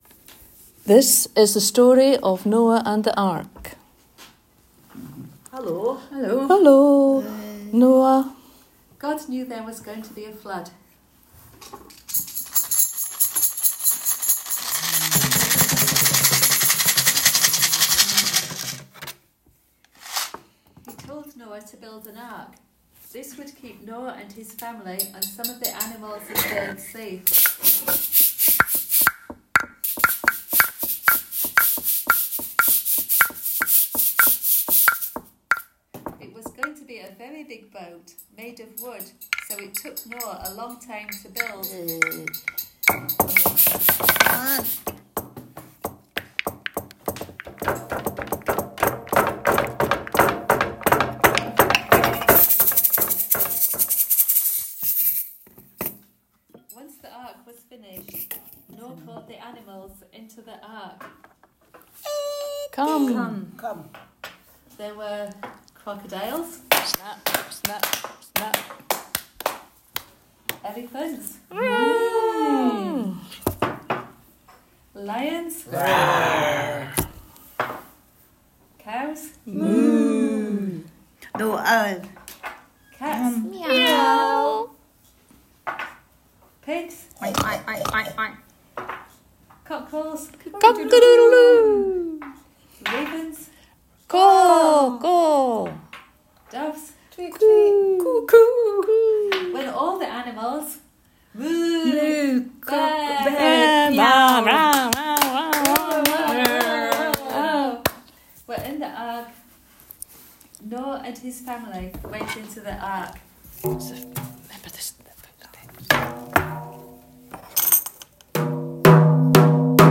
The story of Noah and The Ark with sounds